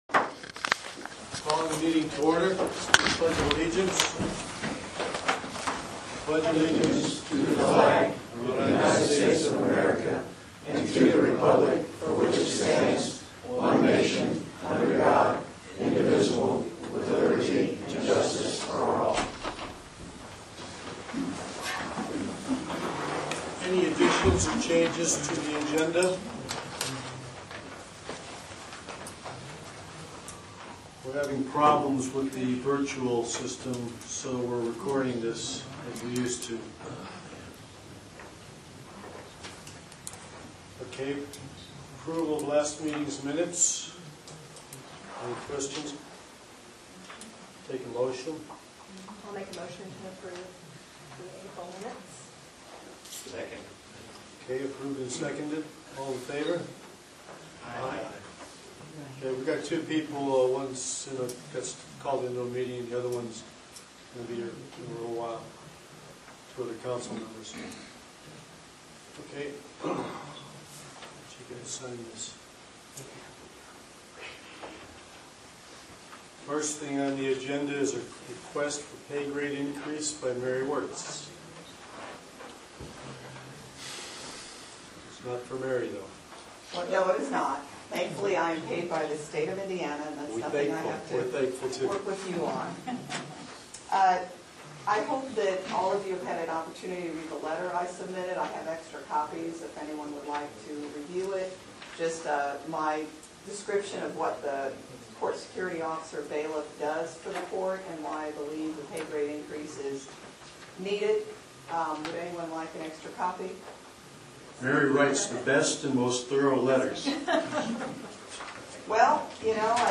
Short meeting – about 40 minutes. Audio of the Meeting.